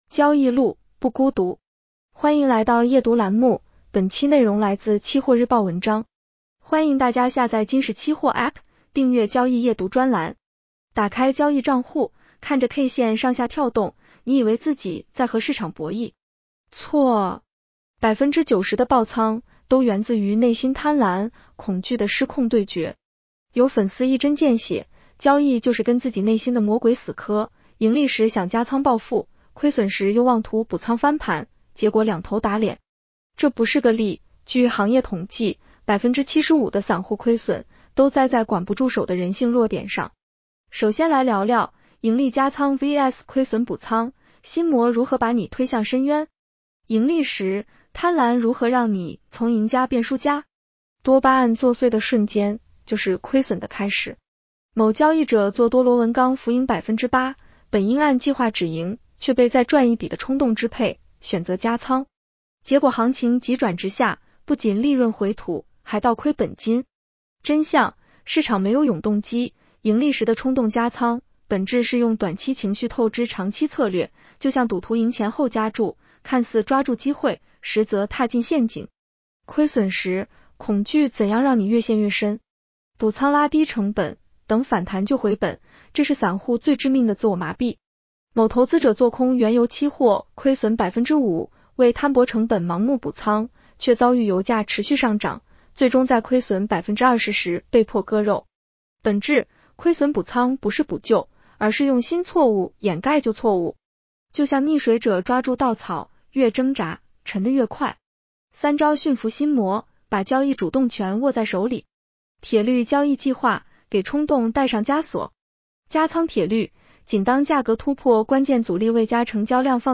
【期货交易夜读音频版】
女声普通话版 下载mp3